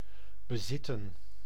Ääntäminen
US : IPA : [oʊn] UK : IPA : /ˈəʊn/